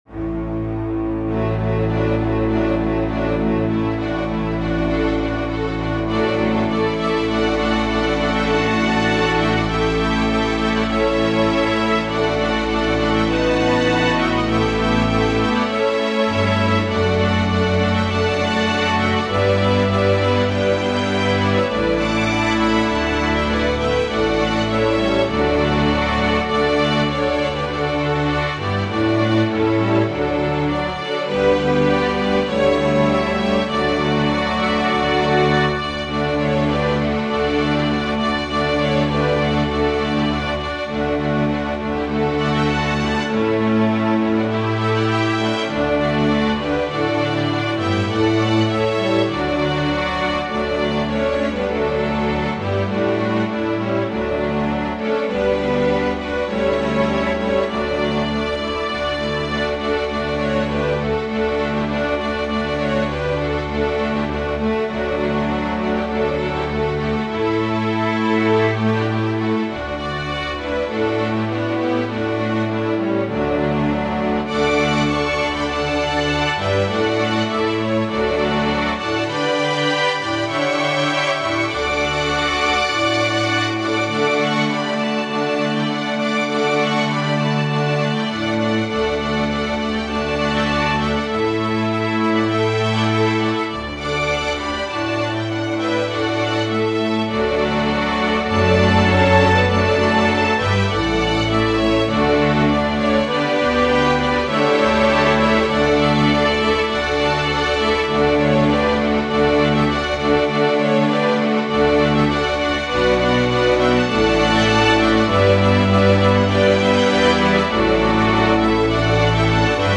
Organ/Organ Accompaniment